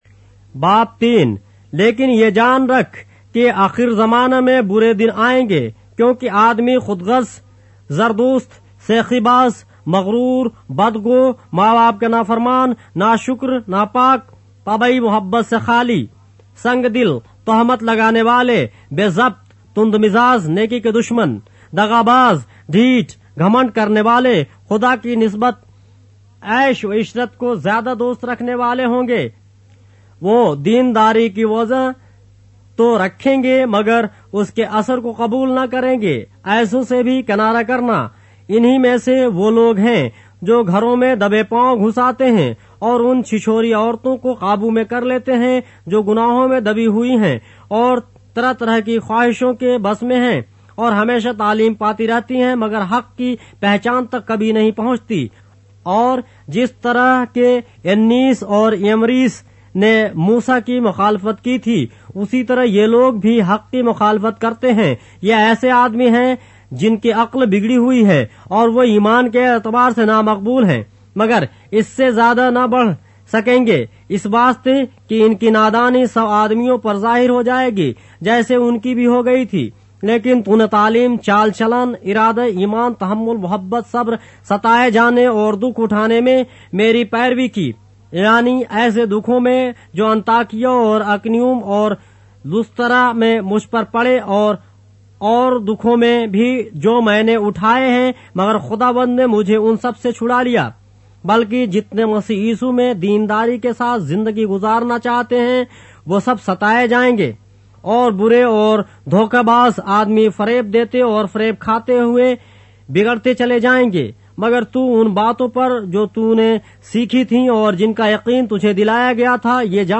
اردو بائبل کے باب - آڈیو روایت کے ساتھ - 2 Timothy, chapter 3 of the Holy Bible in Urdu